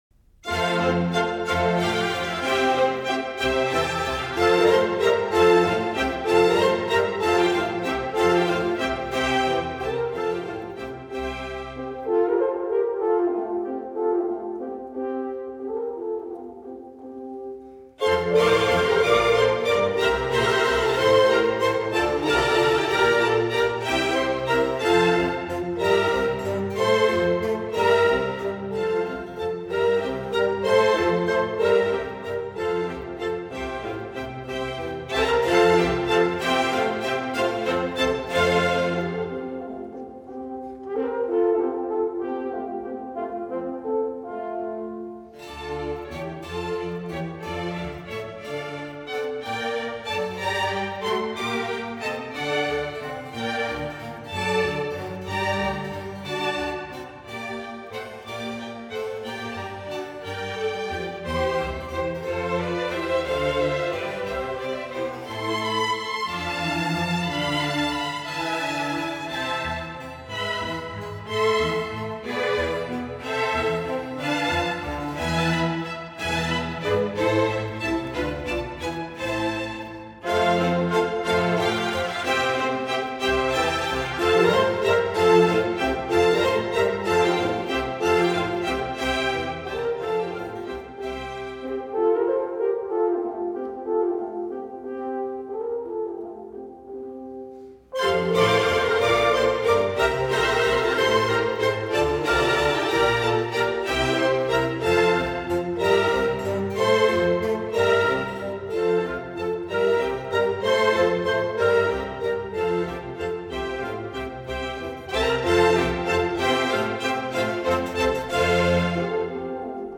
小步舞曲
同前一乐章一样，在最早的版本中没有速度标记，也是以圆号和乐队的协奏为主，不过更具备舞蹈性，是彬彬有礼的小步舞曲。